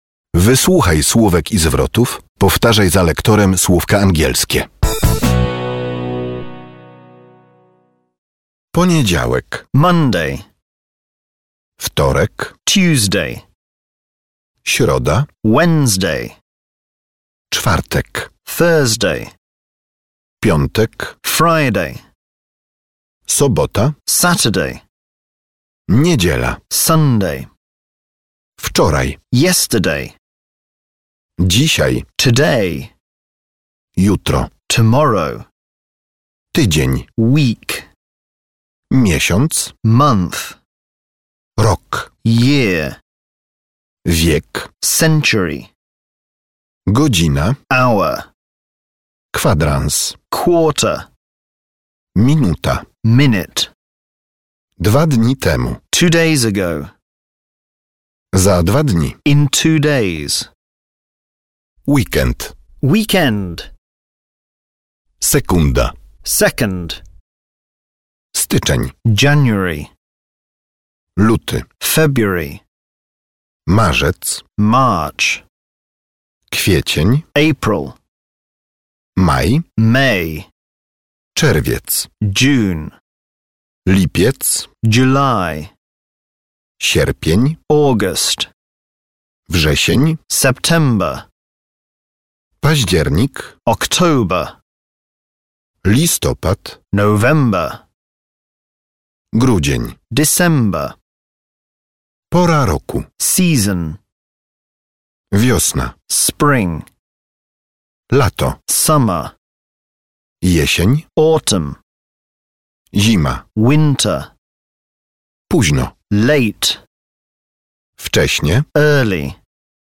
Posłuchaj, jak wymawiać dni tygodnia, nazwy miesięcy i pory roku po angielsku. Nagranie pochodzi z kursu Angielski.